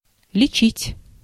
Ääntäminen
France: IPA: [tʁe.te]